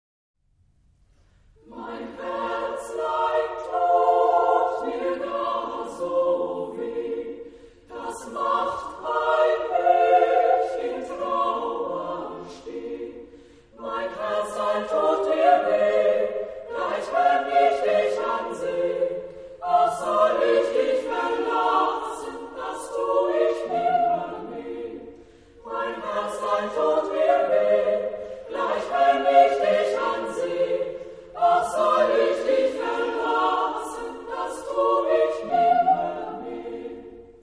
Genre-Stil-Form: romantisch ; weltlich ; Lied
Chorgattung: SSAA  (4 Frauenchor Stimmen )
Tonart(en): b-moll